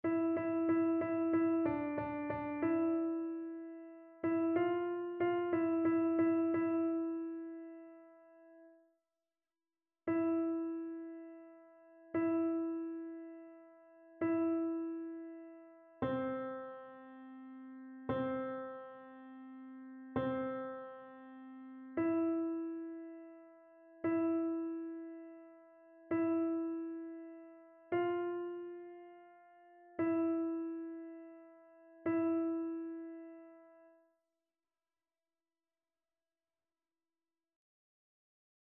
annee-b-temps-ordinaire-26e-dimanche-psaume-18-alto.mp3